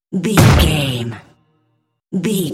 Cinematic stab hit trailer
Sound Effects
Epic / Action
Fast paced
Atonal
heavy
intense
dark
aggressive